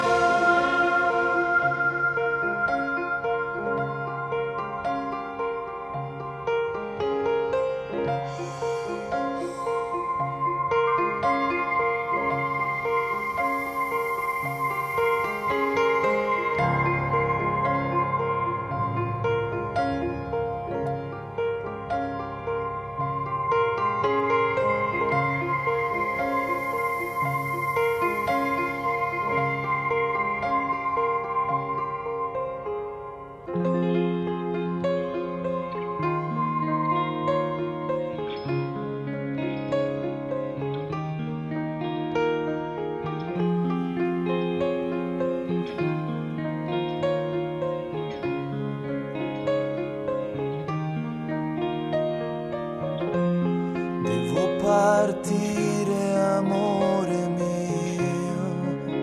Italian prog band
melodic progressive concept albums